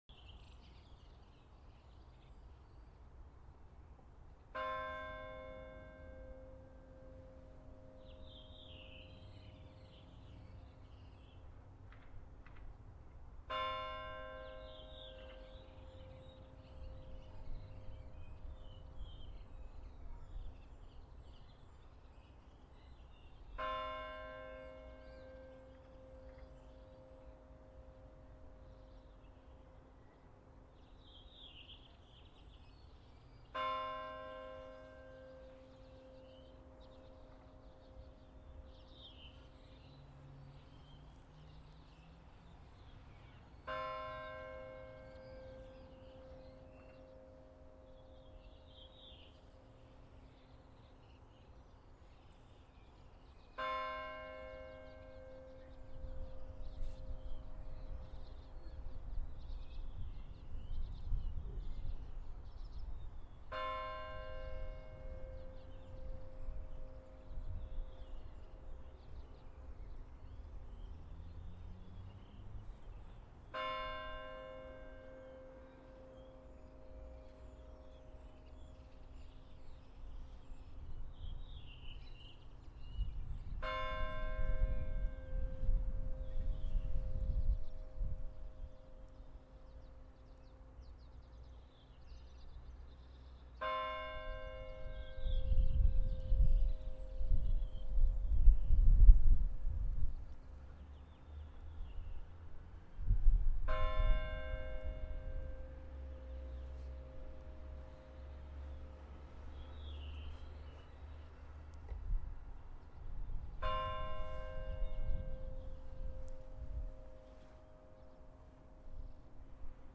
Kirkjan er því nánast eingöngu notuð fyrir útfarir en þó er ekkert því til fyrirstöðu að nýta hana undir aðrar athafnir. 1. Í Fossvogskirkju er ein klukka. Klukkan sveiflast ekki heldur er hamar innan í henni sem slær líkhringingu.